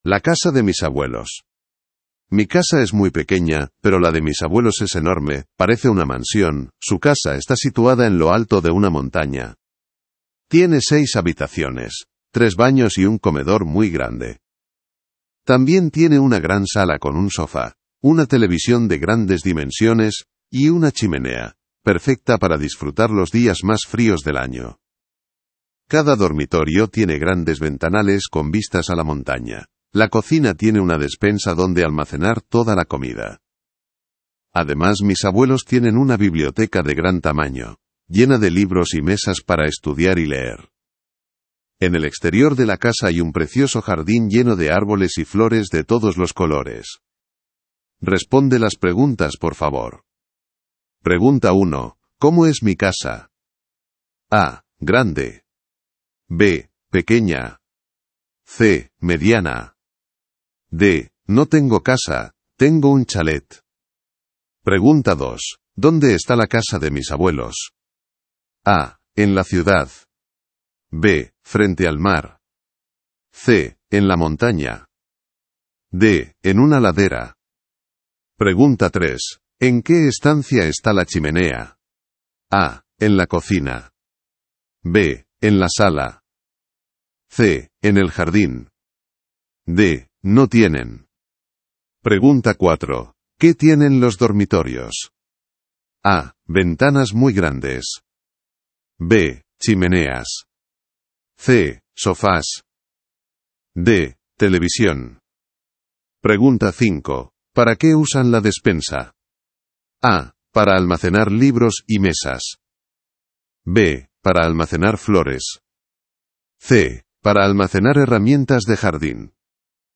Spanien